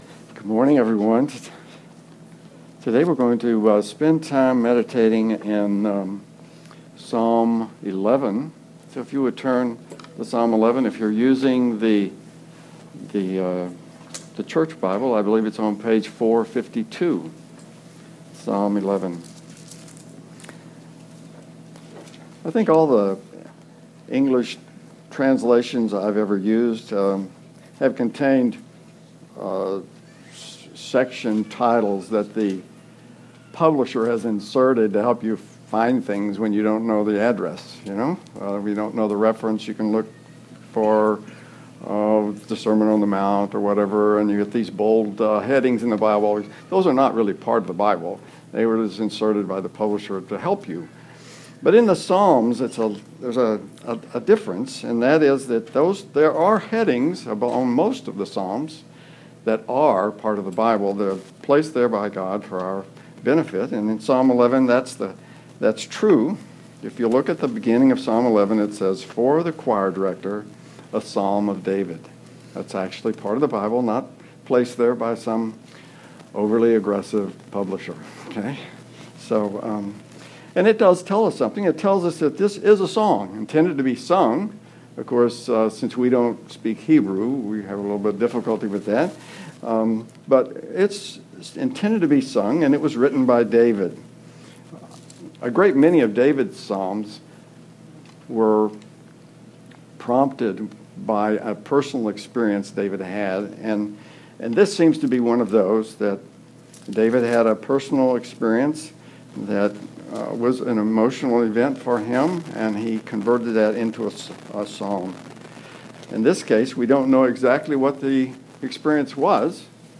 Preacher
2014 ( Sunday AM ) Bible Text